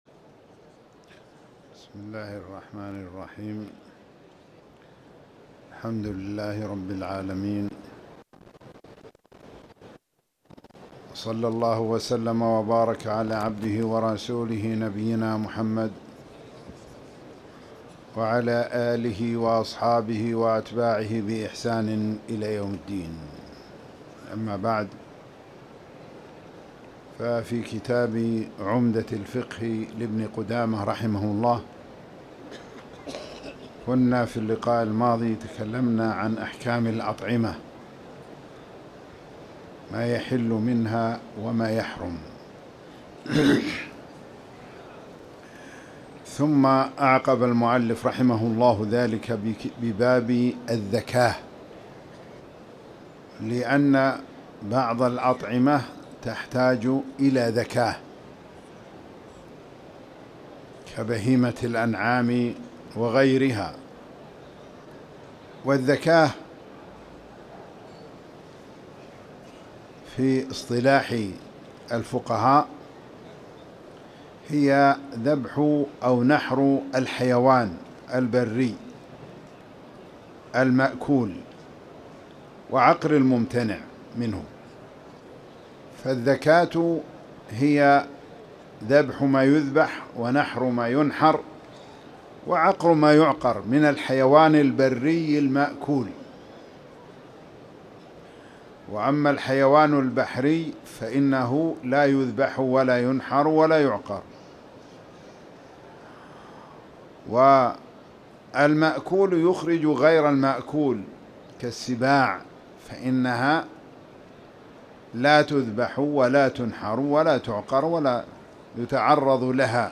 تاريخ النشر ١٤ شعبان ١٤٣٨ هـ المكان: المسجد الحرام الشيخ